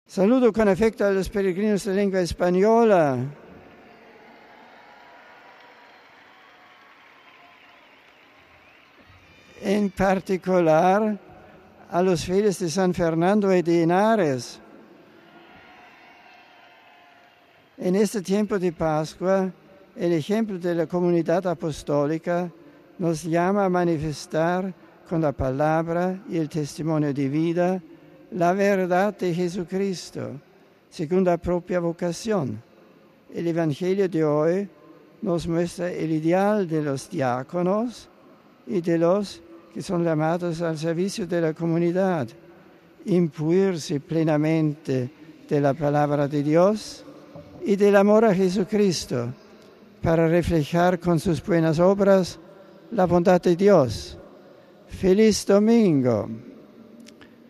Palabras del Papa a los peregrinos de lengua española RealAudio